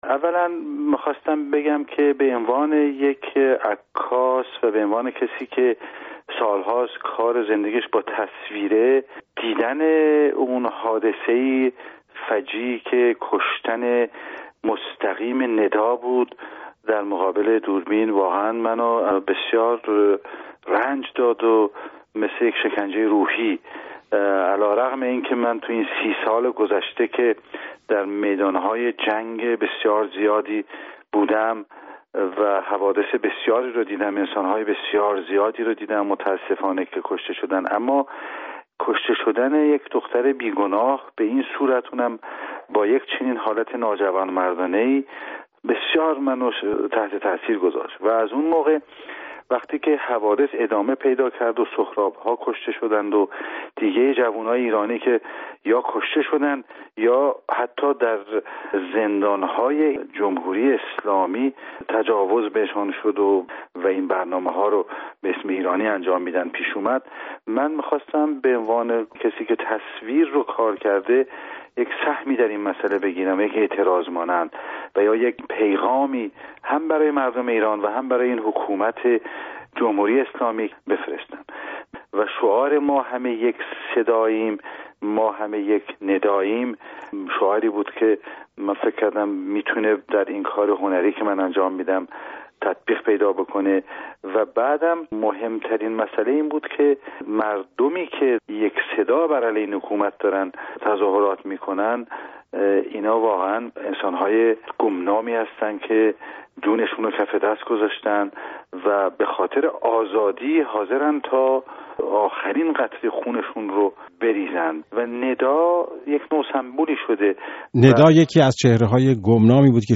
گفت‌وگو با رضا دقتی، خبرنگار عکاس ایرانی در مورد طرح ما همه یک نداییم